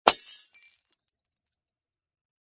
Descarga de Sonidos mp3 Gratis: caida vidrio.